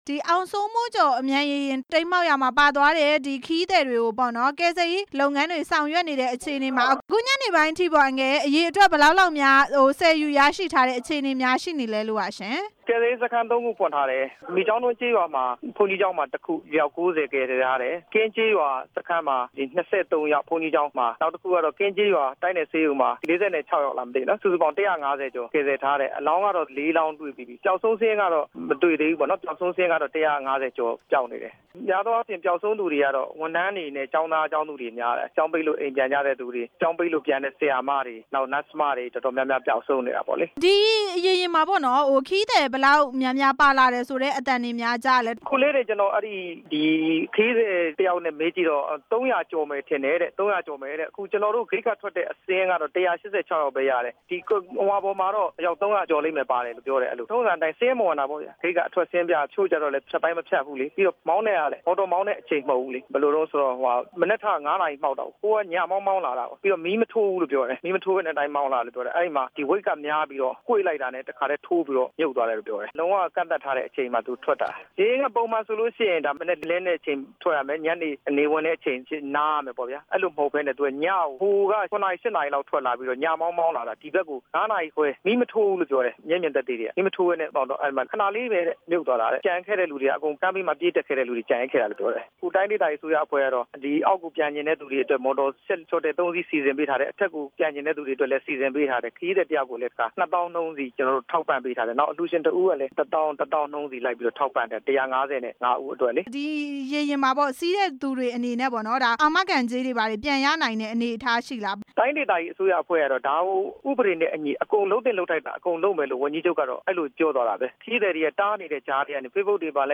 အောင်စိုးမိုးကျော် ရေယာဉ် တိမ်းမှောက်မှု မေးမြန်းချက်